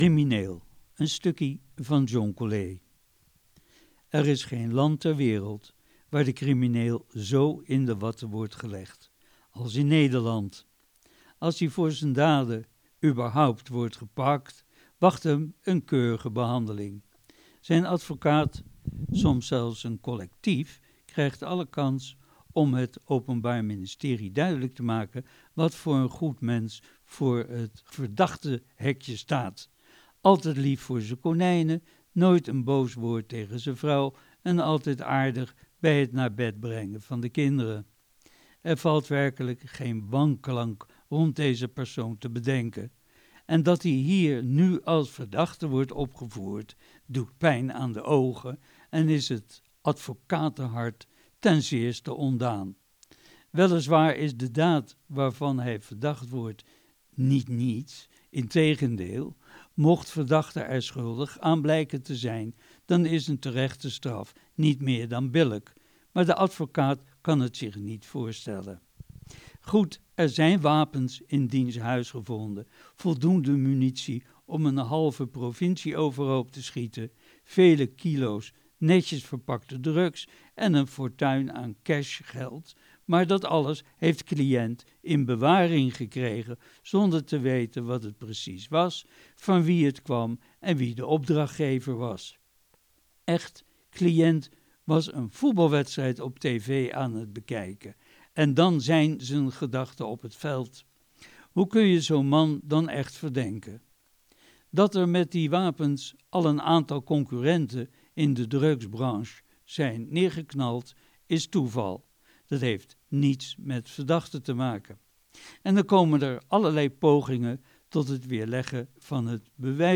Kletskoek wordt iedere vrijdagochtend van 10 uur tot 13 uur "live" vanuitde studio van Radio Capelle wordt uitgezonden.